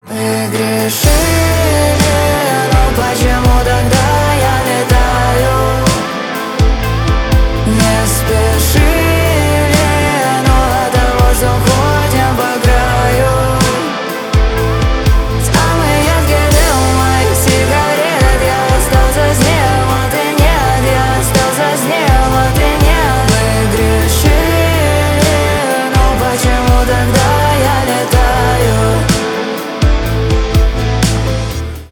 pop rock
дуэт